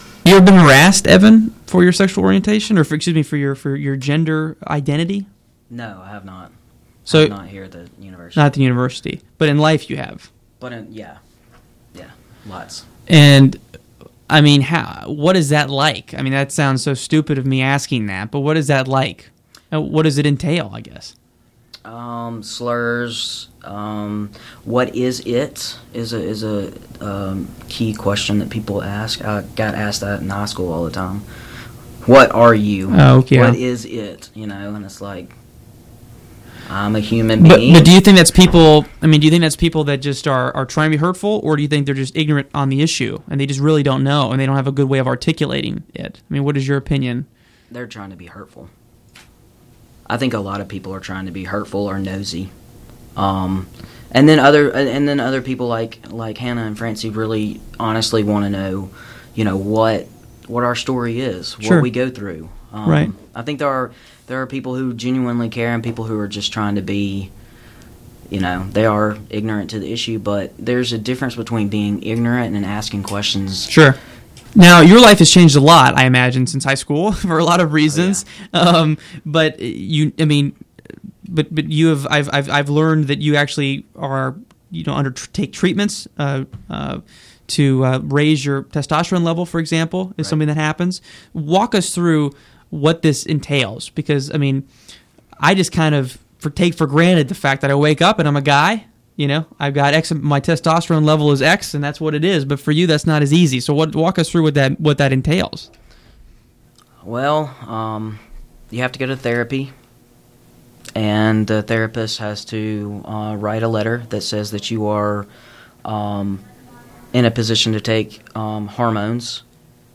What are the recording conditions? This is part two of a two part conversation that was originally broadcast on Capstone News Now on WVUA-FM in Tuscaloosa, AL on Oct, 21 2014.